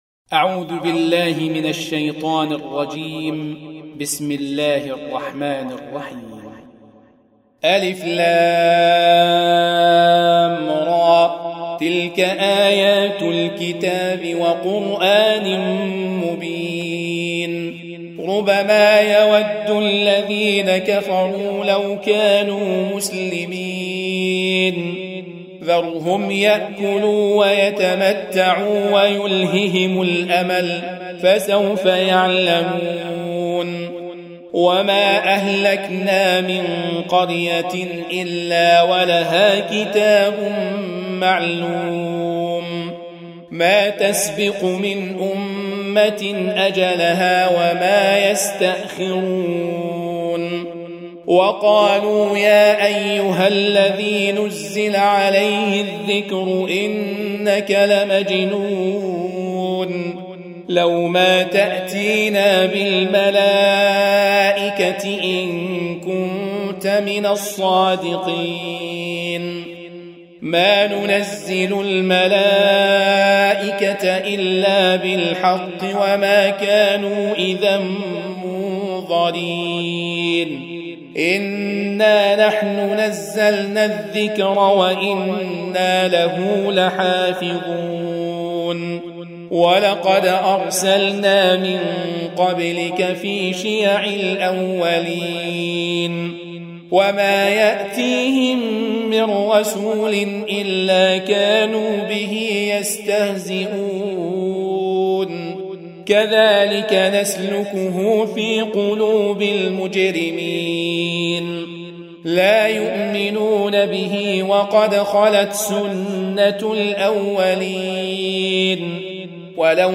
Surah Sequence تتابع السورة Download Surah حمّل السورة Reciting Murattalah Audio for 15. Surah Al-Hijr سورة الحجر N.B *Surah Includes Al-Basmalah Reciters Sequents تتابع التلاوات Reciters Repeats تكرار التلاوات